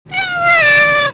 Tags: sfx nekomimi movie effects Wolfshaven Pictures